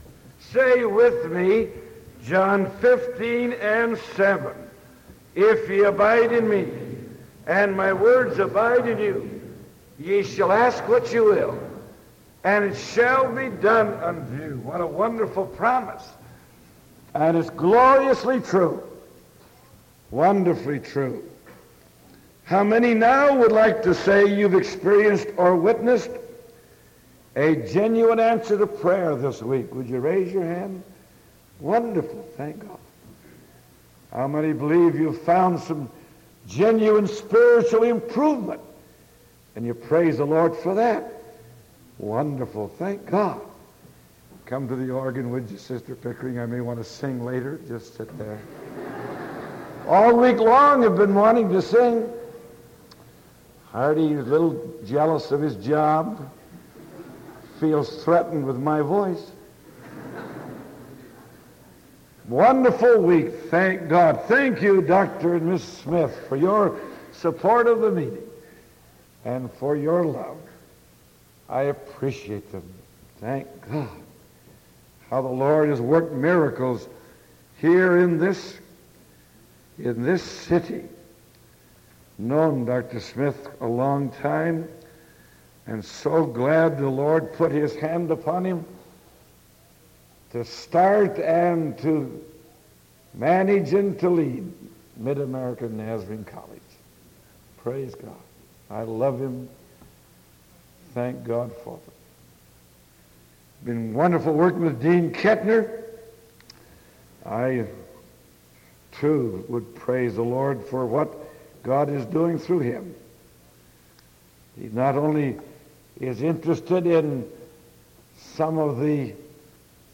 Sermon November 2nd 1975 PM